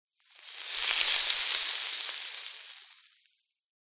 Звуки кислоты
Звук пузырящейся кислоты